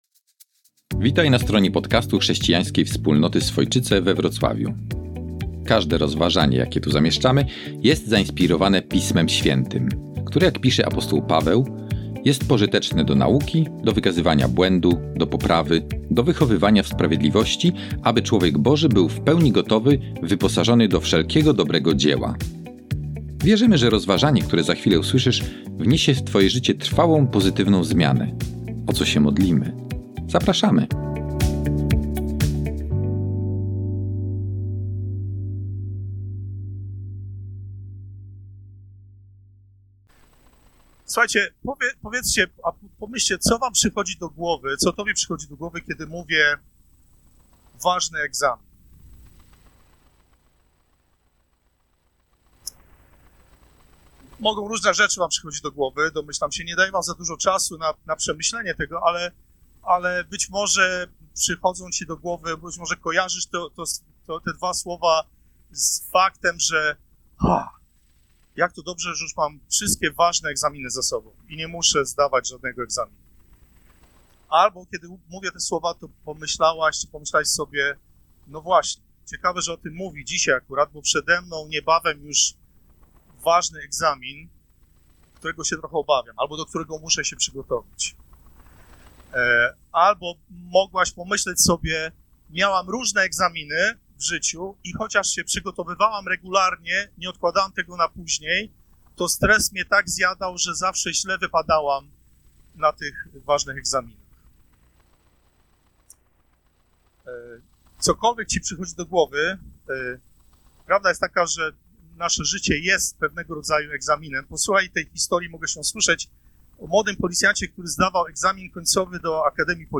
Nauczanie z dnia 12 kwietnia 2026